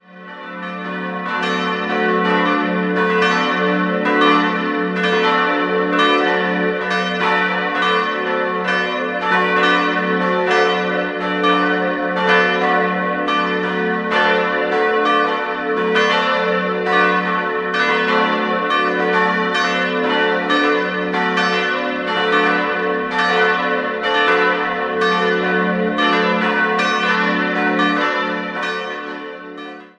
Die Kirche erfuhr im Lauf der Jahrhunderte mehrere Veränderungen. 4-stimmiges Geläut: fis'-a'-h'-d'' Glocke 3 wurde 1659 von Schelchshorn in Regensburg gegossen, alle anderen goss Friedrich Wilhelm Schilling im Jahr 1961.